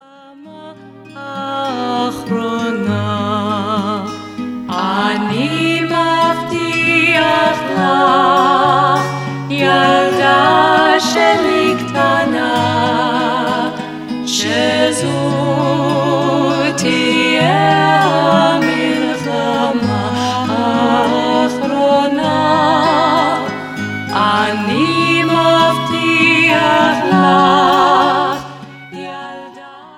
Beautiful melodies, powerful themes and perfect harmonies.